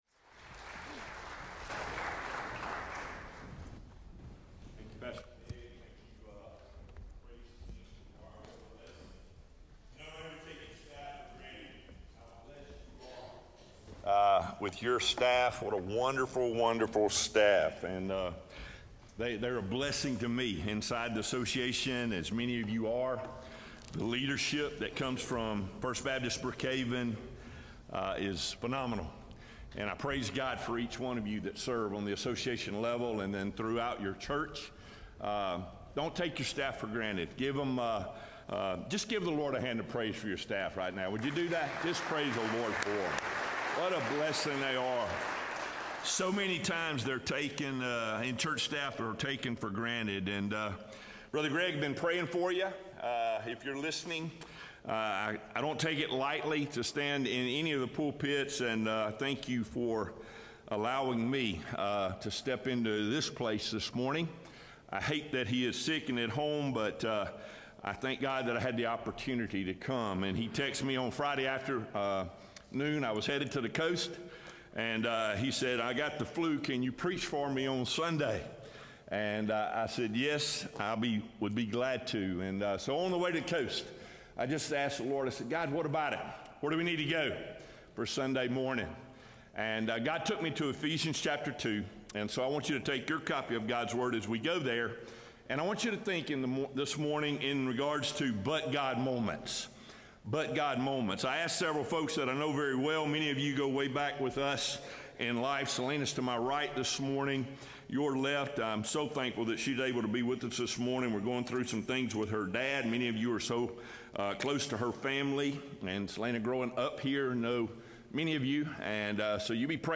Sermons
February-2-2025-Sermon-Audio.mp3